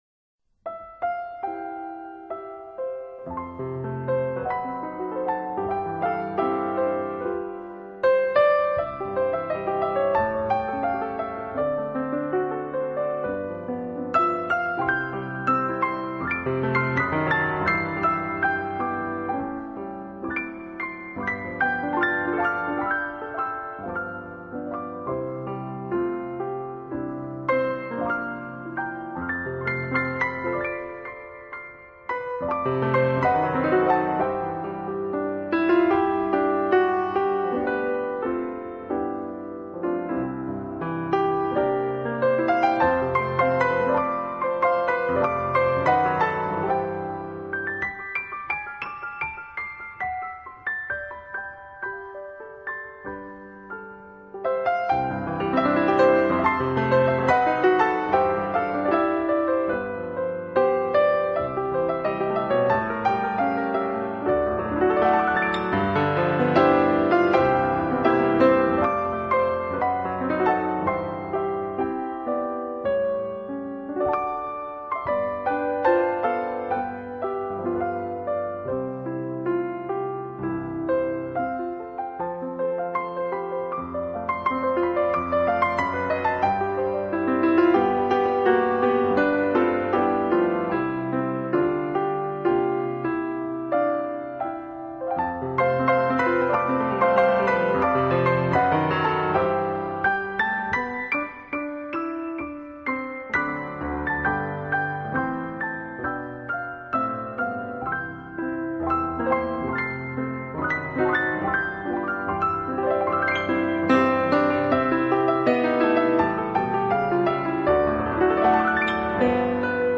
【钢琴纯乐】
音乐类型：Instrumental 钢琴